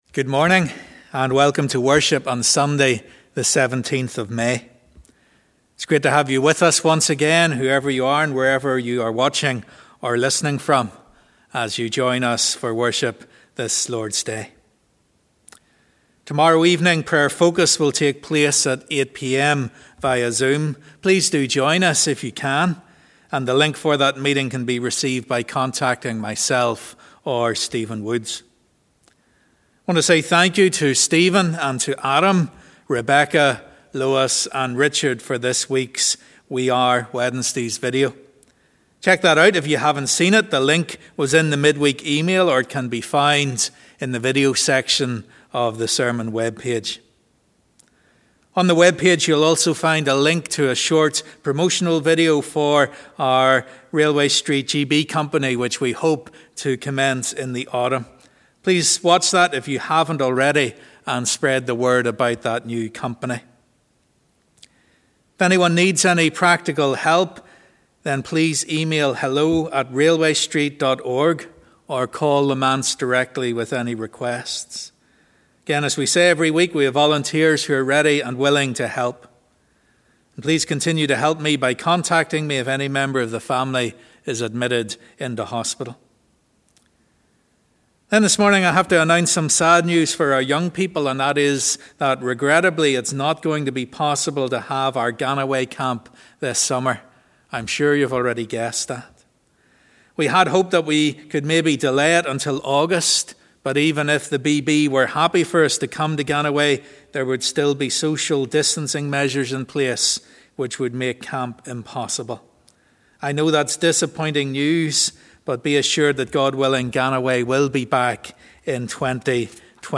Sunday 17th May 2020 Morning Service